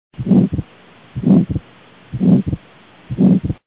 Südametoonide helisalvestused (H.)
Kopsuarteri stenoos  Ahenemisel turbulentsus suureneb